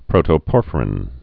(prōtō-pôrfə-rĭn)